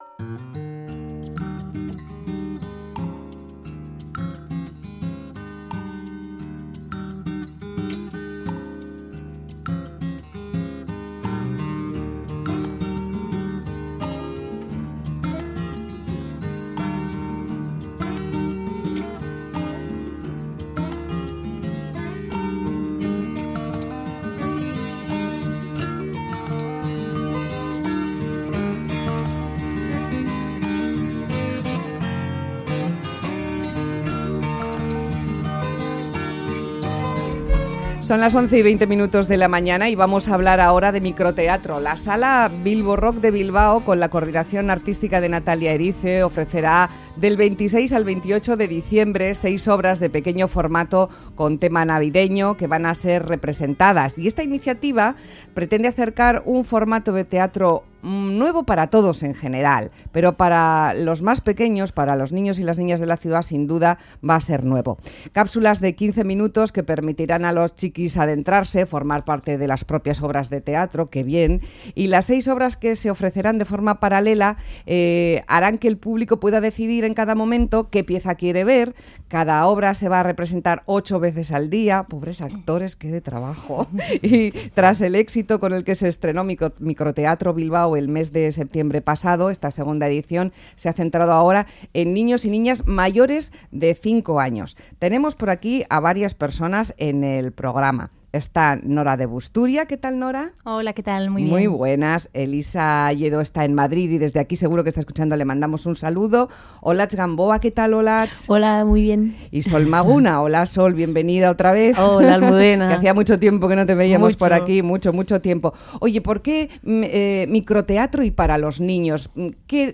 Radio Euskadi MÁS QUE PALABRAS Microteatro para niños Última actualización: 20/12/2014 12:41 (UTC+1) Conocemos el Ciclo de Microteatro que la sala Bilborock de Bilbao ofrecerá del 26 al 28 de diciembre. Son 6 obras de pequeño formato con tema navideño, serán representadas para el público infantil. En nuestros micrófonos, una pequeña muestra